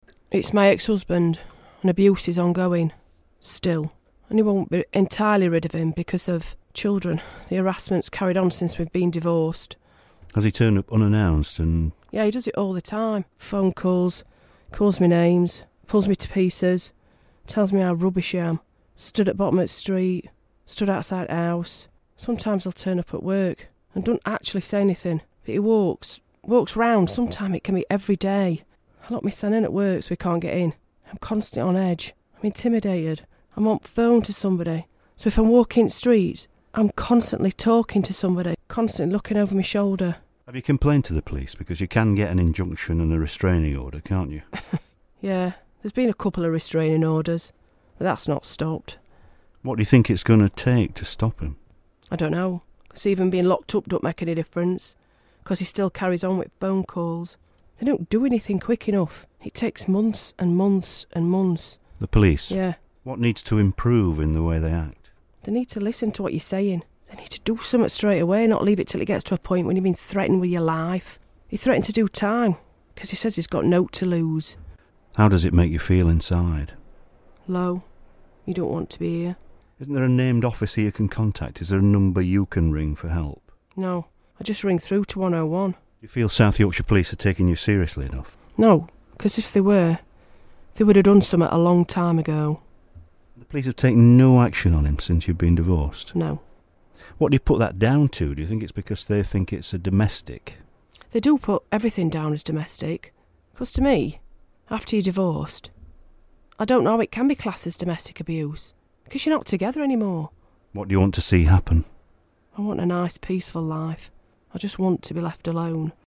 New figures show there's been an increase in the number of victims of stalking in South Yorkshire. One woman told us how she was stalked by her ex-husband.